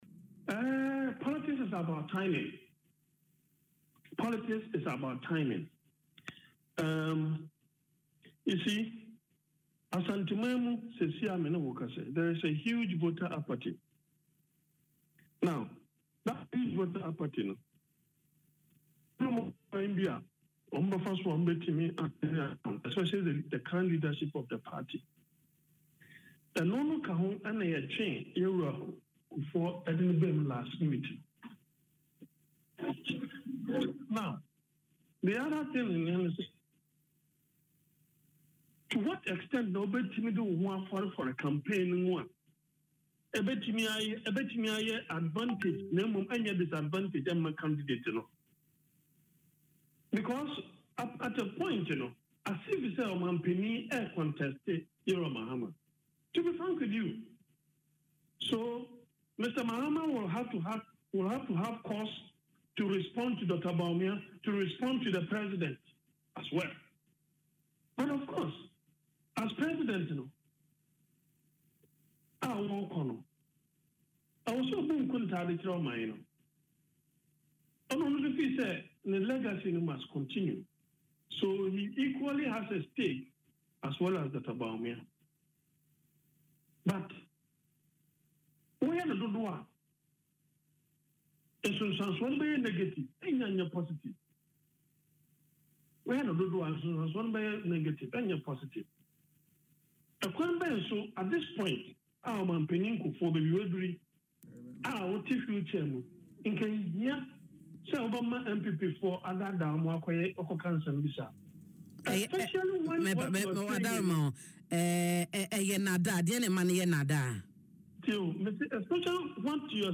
He made these remarks on Adom FM’s morning show Dwaso Nsem Friday following Kufuor’s several endorsement of the New Patriotic Party(NPP) flagbearer, Dr Mahamudu Bawumia.